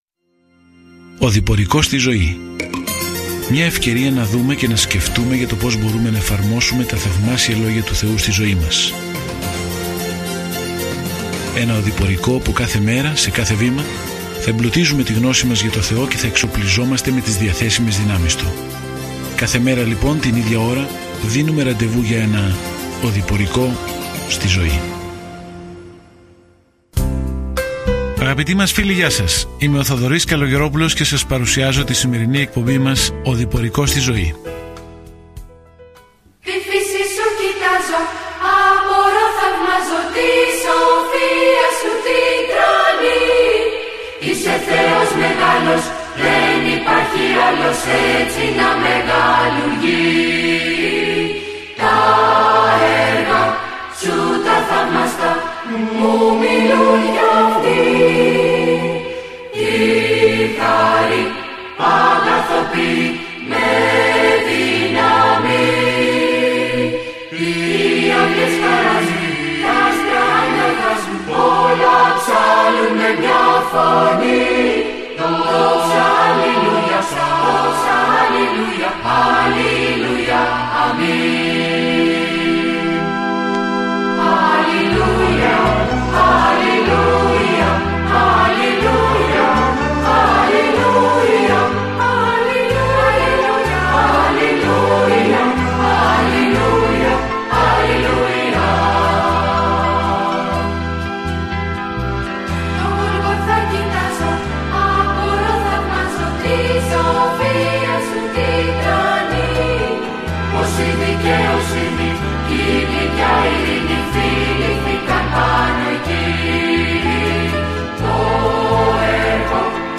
Καθημερινά ταξιδεύετε στην Εσθήρ καθώς ακούτε την ηχητική μελέτη και διαβάζετε επιλεγμένους στίχους από τον λόγο του Θεού.